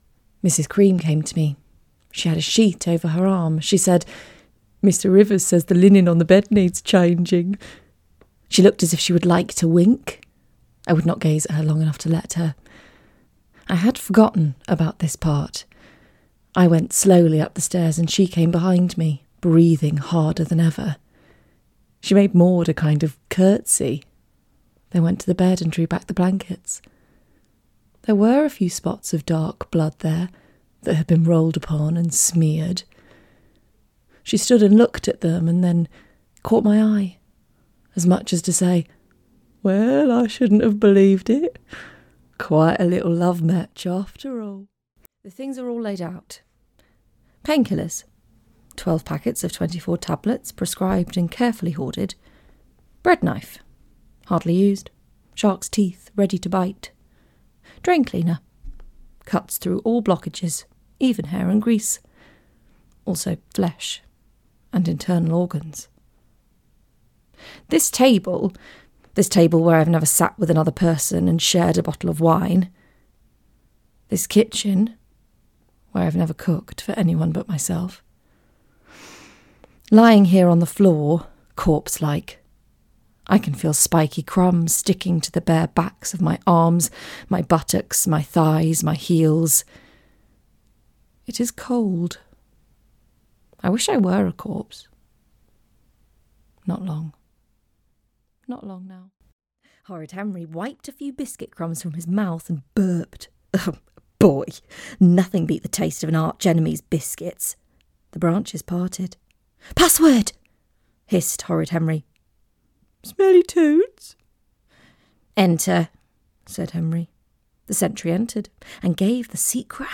Audiobook Reel
• Native Accent: Geordie, R.P
• Home Studio
With an instinctive grasp of character, she too has a talent for the off-beat and the quirky.